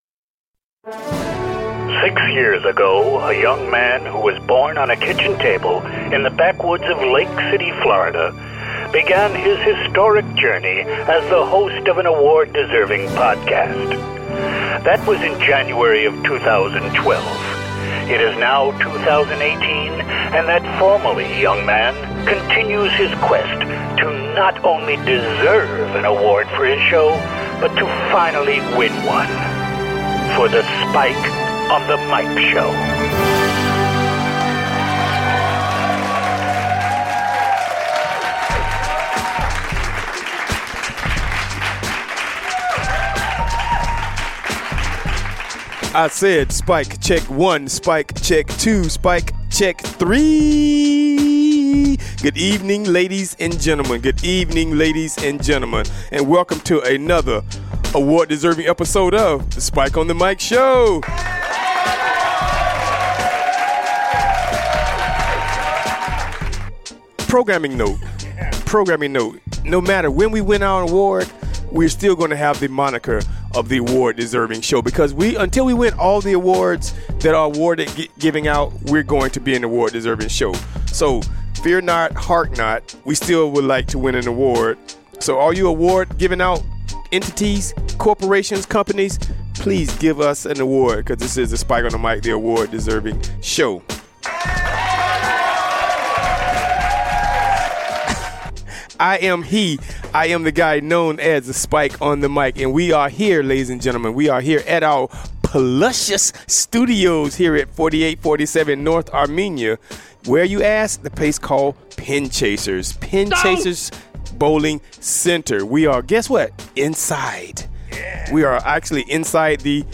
Various locations in Tampa.